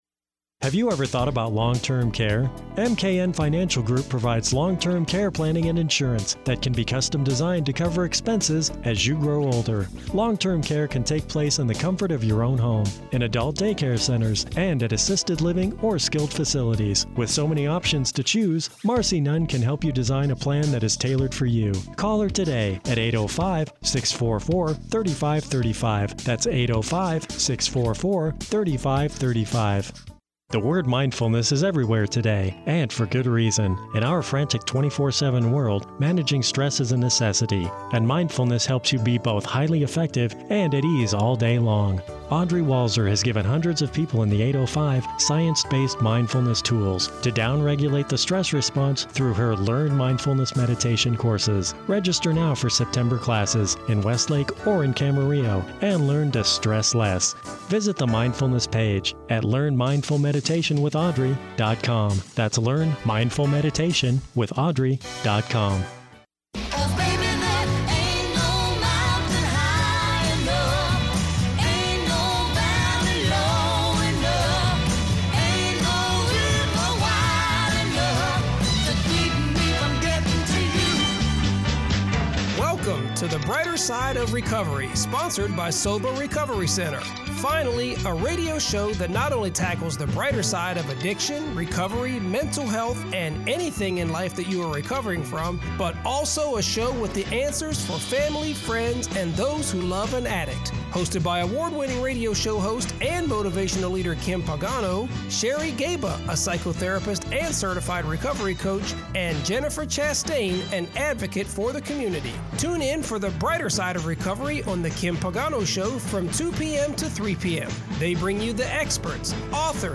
Finally, a radio show that not only tackles the brighter side of addiction, recovery, and mental health, but a show with the answers for the family, friends, and those who love an addict.